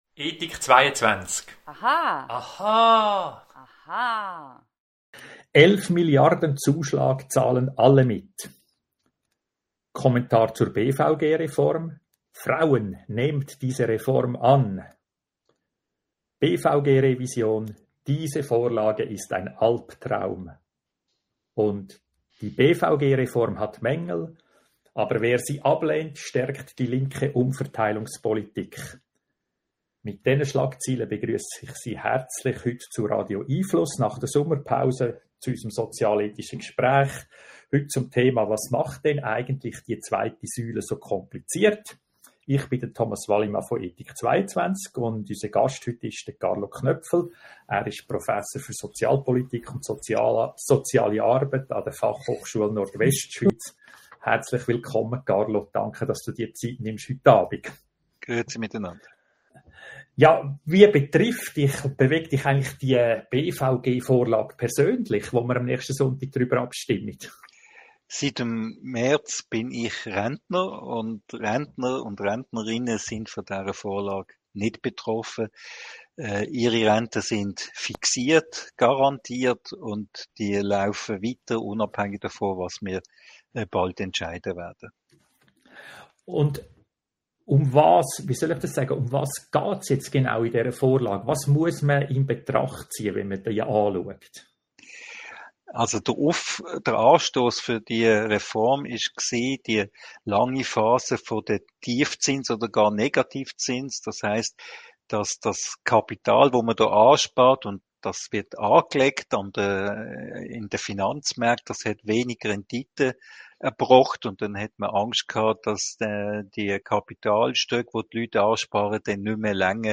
Radio🎙einFluss Audio-Gespräche informiert!